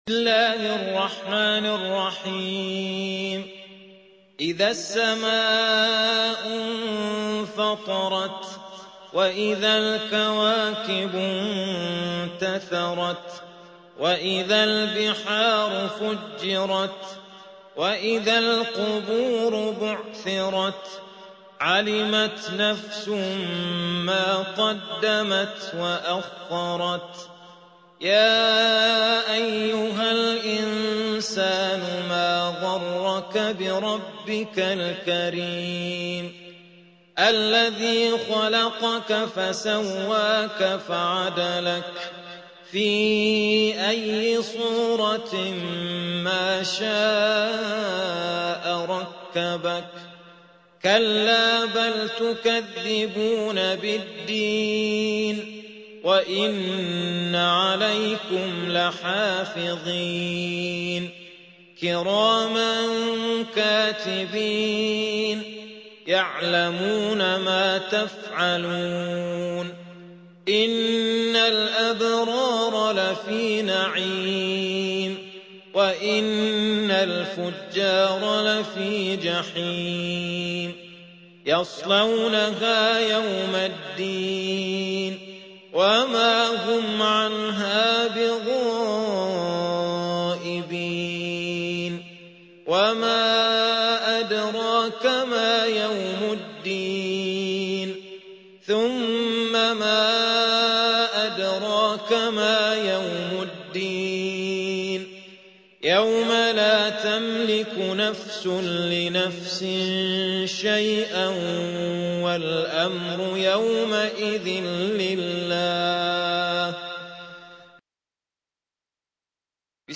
82. سورة الانفطار / القارئ